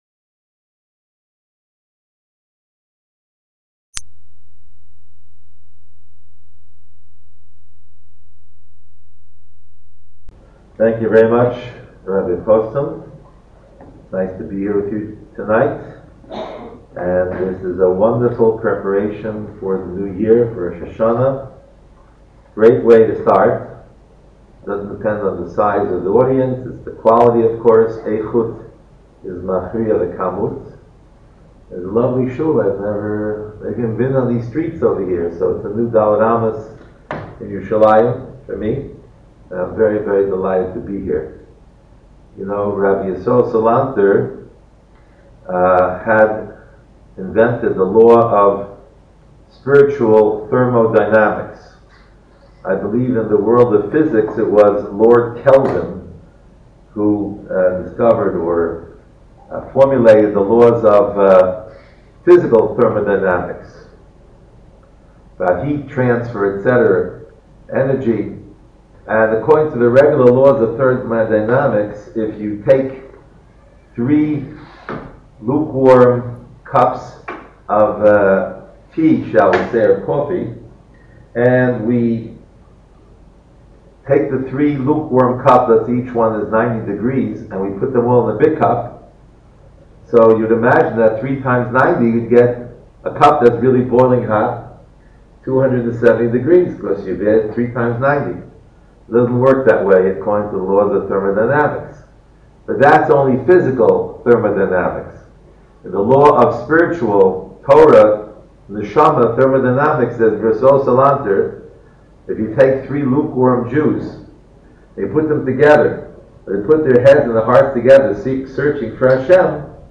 So I created an audio mp3 recording at a higher volume.